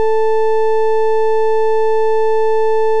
OPL waveforms
Half-sine. Waveform 1. Scaling PD variation. Zoom out 2x for the "on/off sine", zoom in 2x after to remove the negative half (the padding line remaining such).